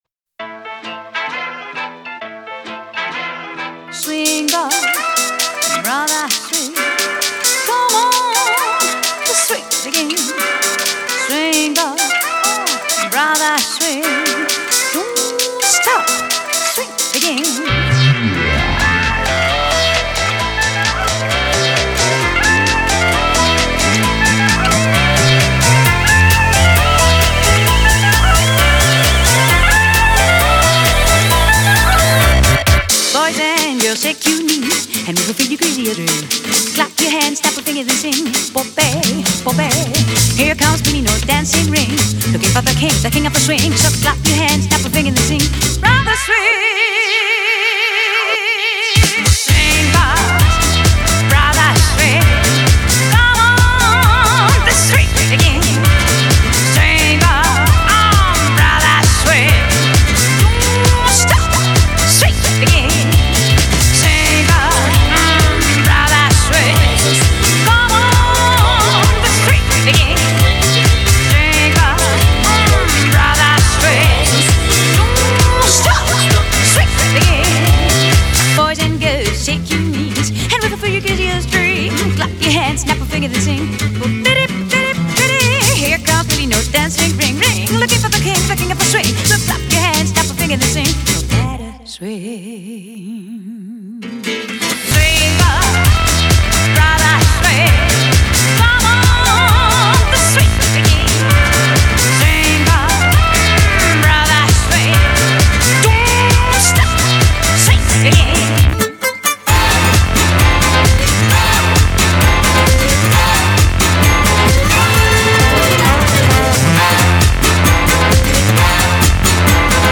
BPM132
Audio QualityPerfect (High Quality)
quaint little electro swing tune